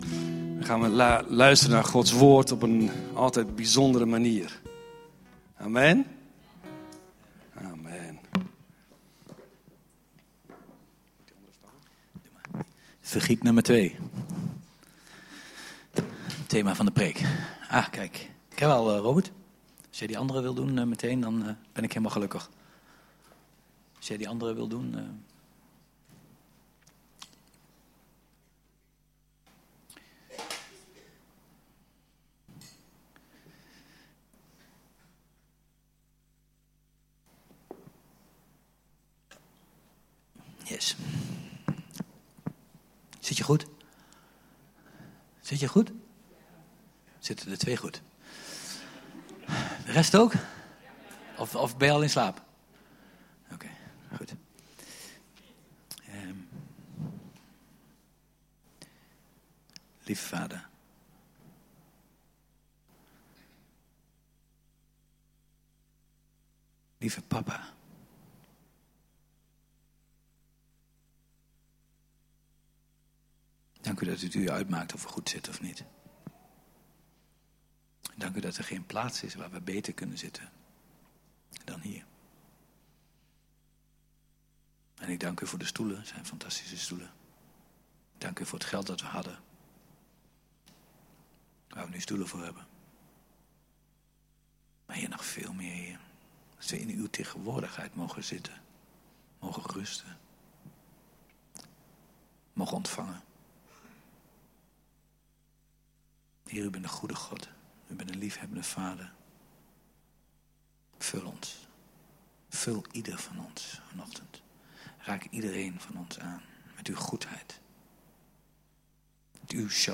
God nodigt ons uit om ons oude water in de put te gooien en nieuw vers water op te pompen. Luister de preek nu!